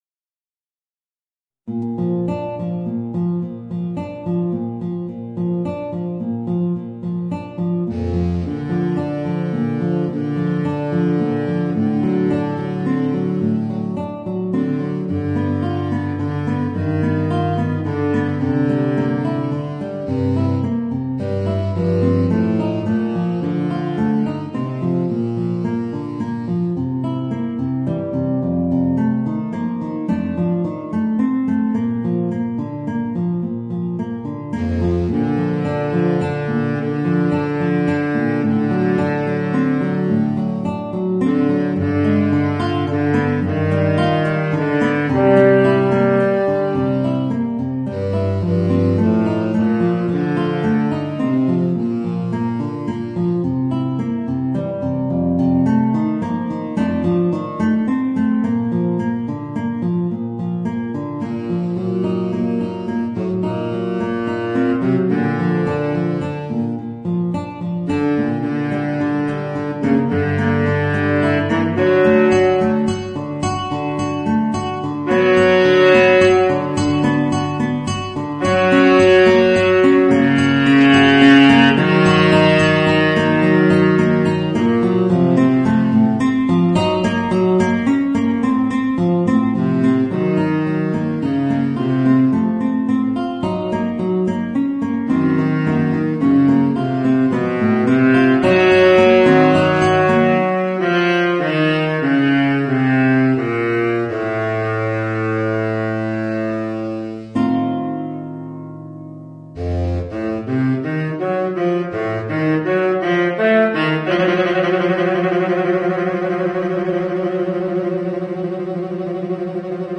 Voicing: Guitar and Baritone Saxophone